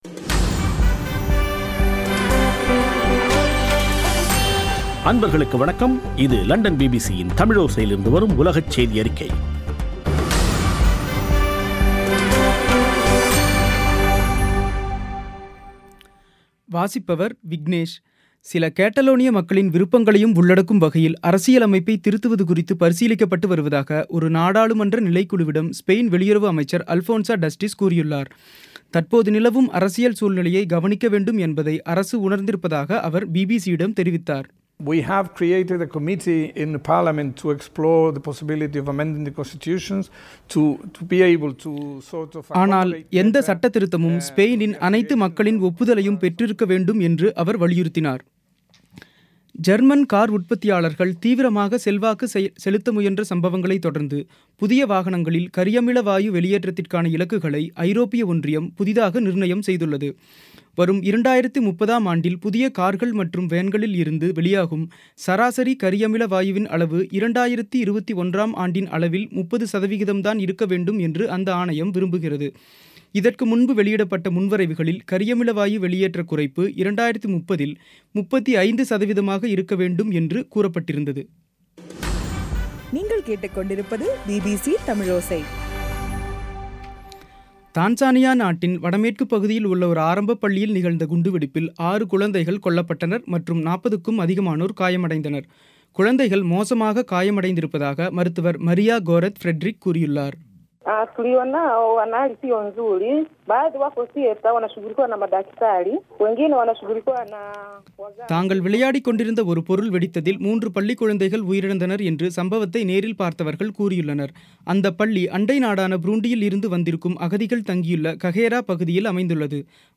பிபிசி தமிழோசை செய்தியறிக்கை (08/11/2017)